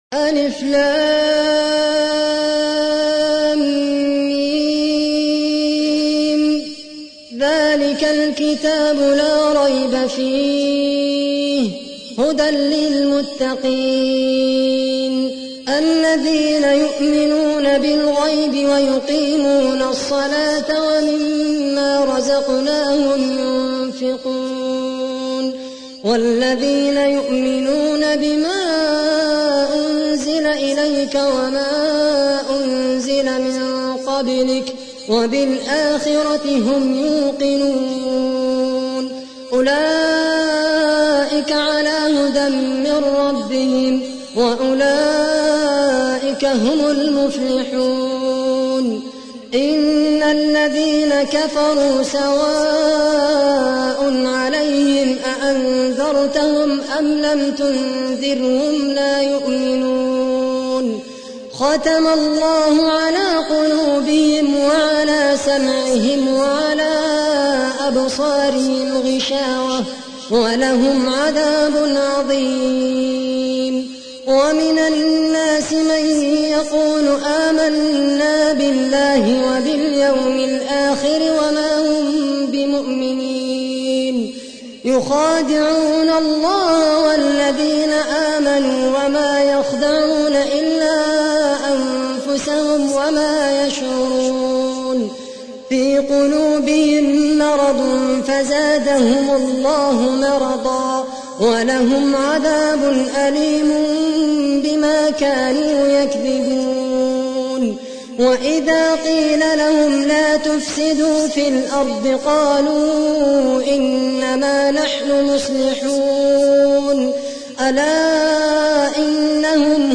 تحميل : 2. سورة البقرة / القارئ خالد القحطاني / القرآن الكريم / موقع يا حسين